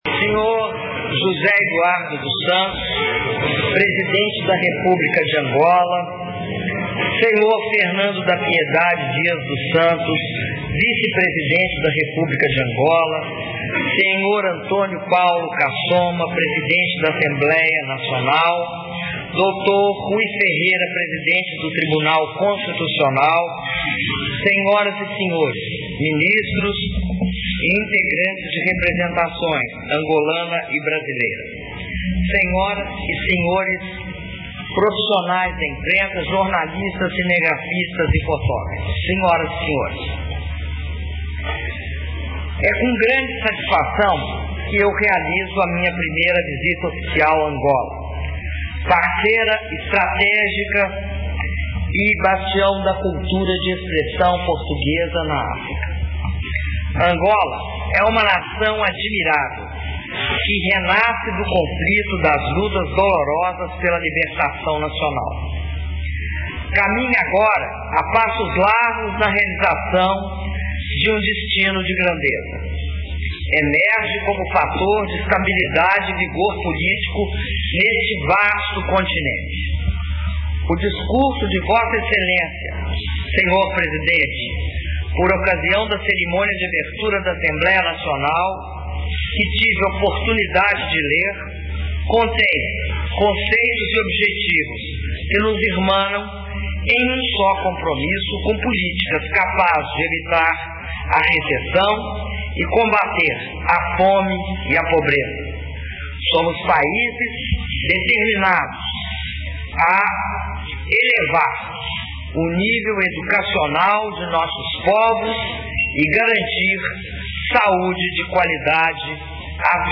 Discurso da Presidenta da República, Dilma Rousseff, durante almoço em sua homenagem oferecido pelo presidente de Angola, José Eduardo dos Santos - Luanda/Angola